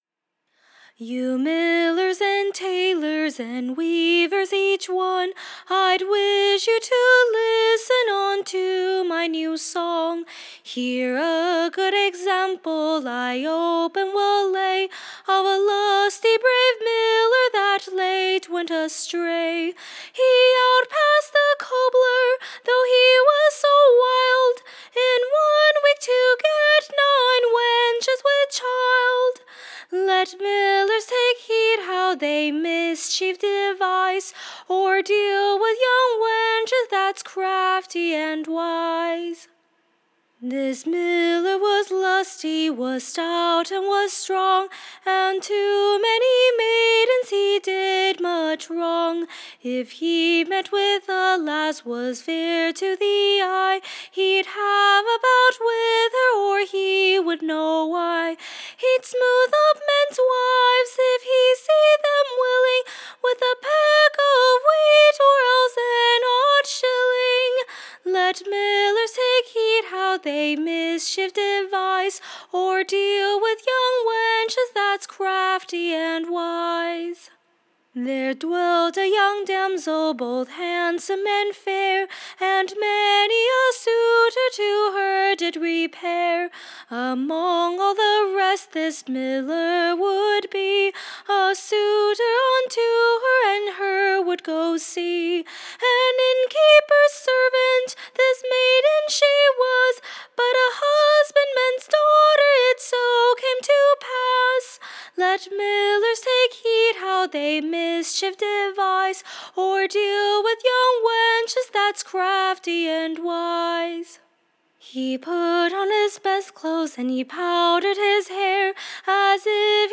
EBBA 37725 - UCSB English Broadside Ballad Archive
Recording Information Ballad Title THE / Crafty Maid of the West: / OR, / The lusty brave Miller of the Western Parts finely trapan'd. / A merry new Song to fit Young-men and Maids.